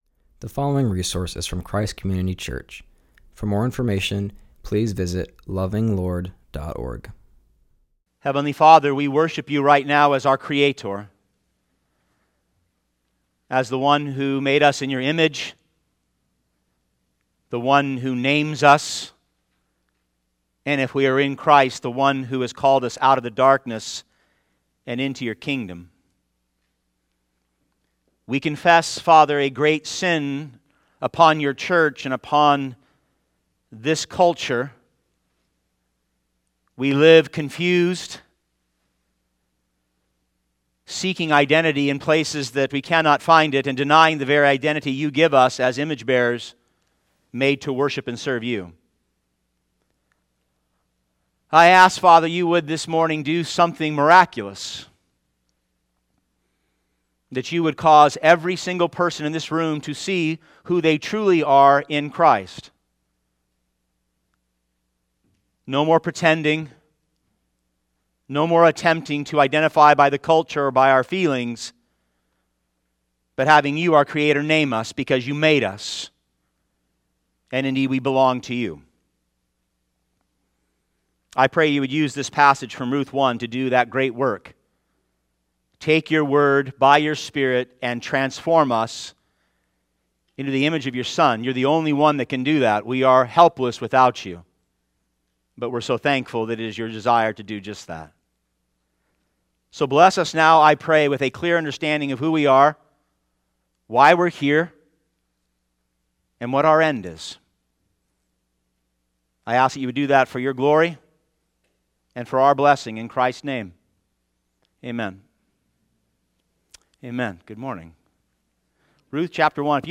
preaches on Ruth 1:19-22.